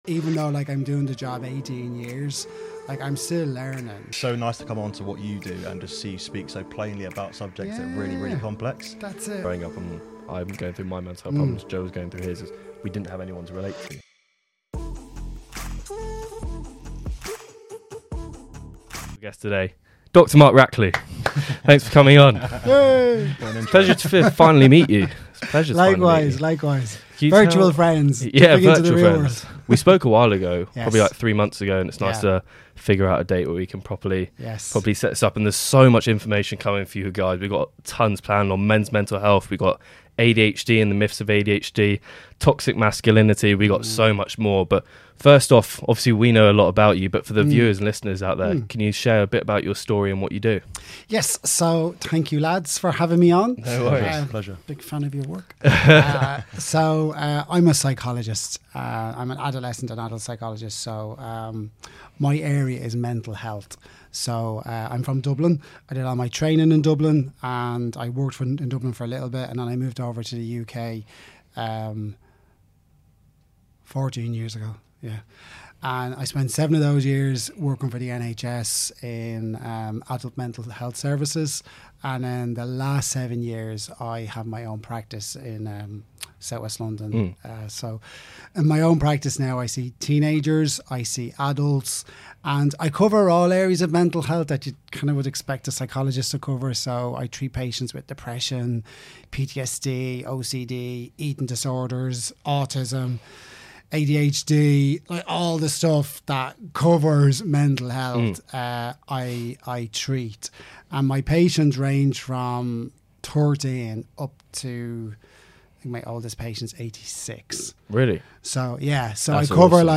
In another revealing segment, we tackle the often-overlooked mental health challenges associated with the UK's reality TV sphere and the resilience needed to endure the pressures of such public exposure. We discuss the heightened awareness around participant vetting processes and the mental health incidents that have reshaped reality TV show protocols. The conversation also delves into strategies for managing the dark side of social media fame, setting boundaries, and the importance of support networks for public figures and influencers.